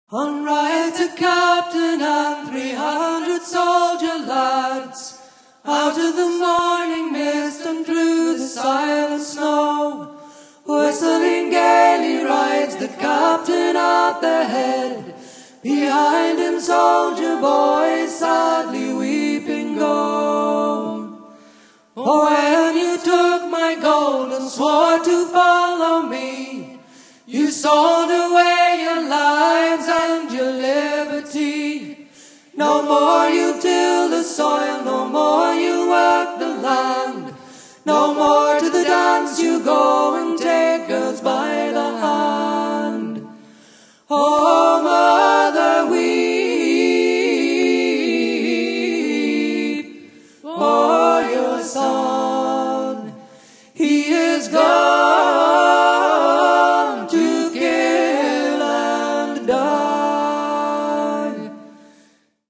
First part, 1:02 sec, mono, 22 Khz, file size: 306 Kb.